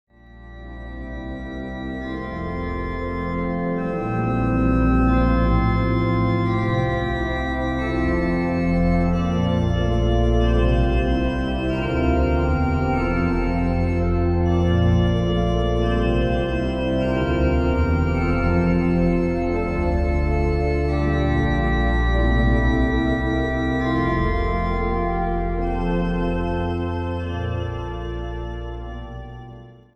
op het orgel van de Evangelisch Lutherse Kerk te Den Haag.